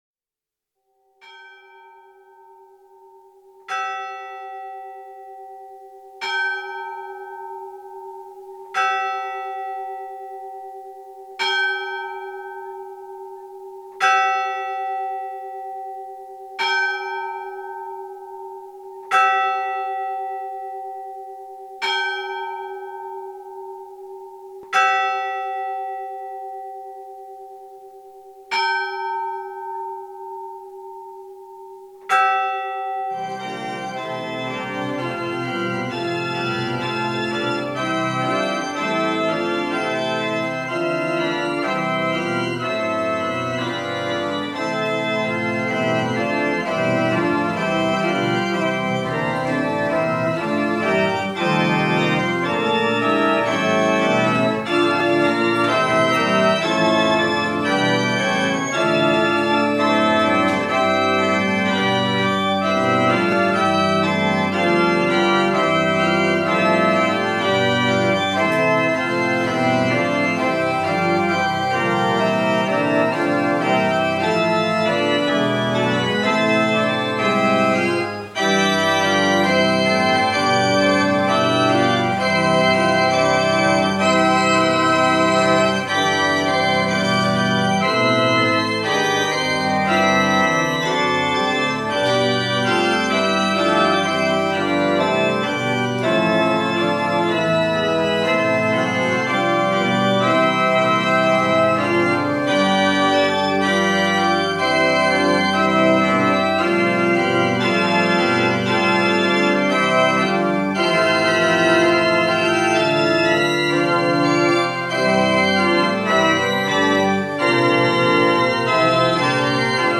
Jumalateenistus 20. aprill 2025